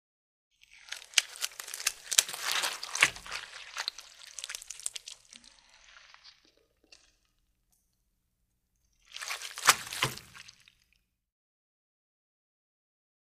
Organ Squish | Sneak On The Lot
Brain Squish; Effect; Moist, Fleshy Organ Movements; Fall To Floor, Close Perspective.